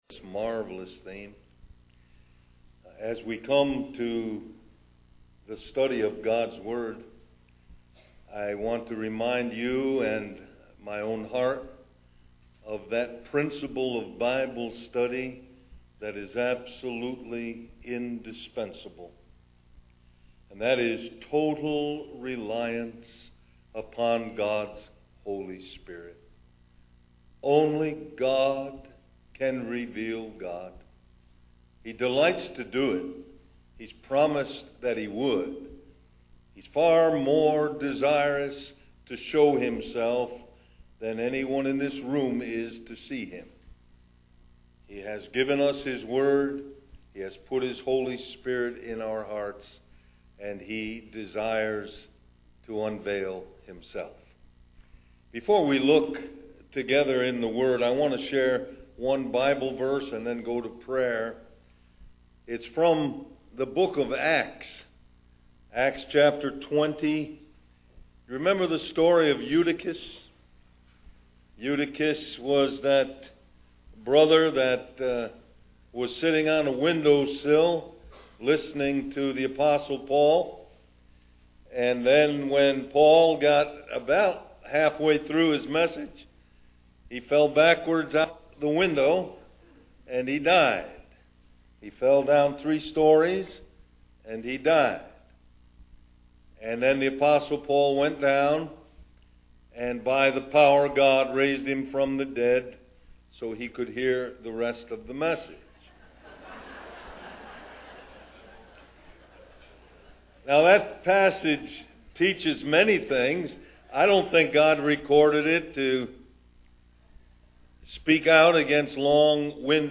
Harvey Cedars Conference